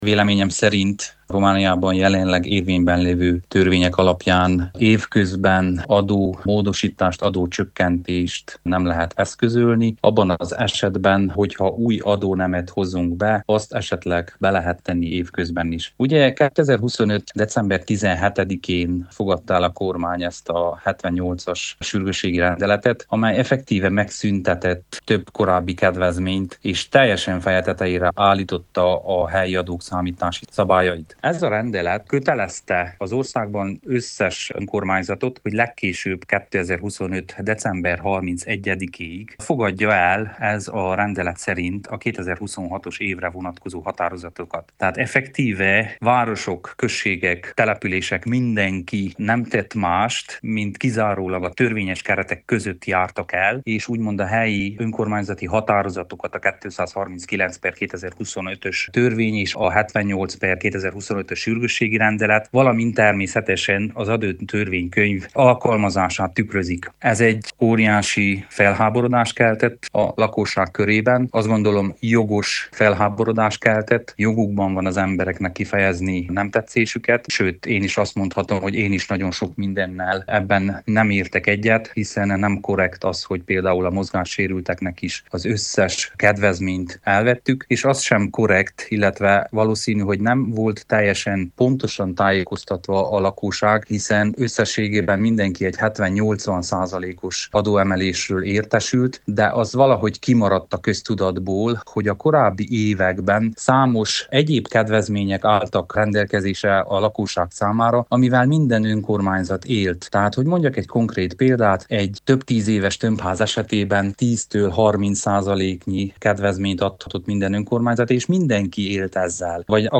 Jelenleg nem látszik arra lehetőség, hogy az idei évben a pénzügyminisztérium változtatni fog az adópolitikáján, vélekedett Bors Béla, Csíkszereda alpolgármestere, aki rádiónknak azt is elmondta, hogy számos önkormányzat előkészítette a tervezetet az adók csökkentésére, de ezt még sehol nem fogadták el.